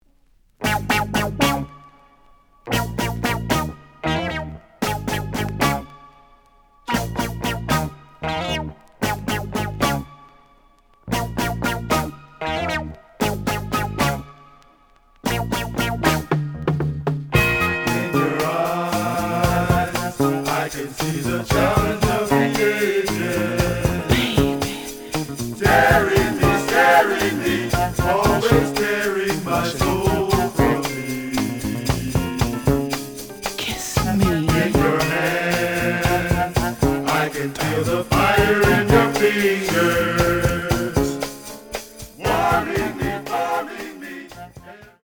試聴は実際のレコードから録音しています。
●Genre: Funk, 70's Funk